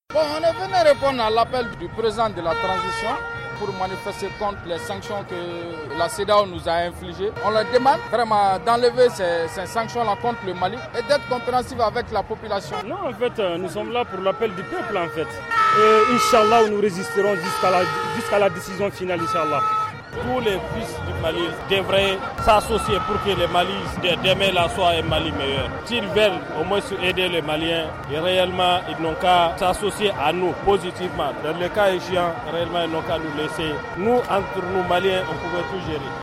Ce sont quelques slogans qu’on pouvait lire sur des pancartes ce vendredi (14/01/22) à la place de l’indépendance de Bamako. Des milliers de Maliens ont ainsi répondu présents à l’appel des autorités de la transition.
VOX-POP-MEETING-SANCTIONS-CEDEAO.mp3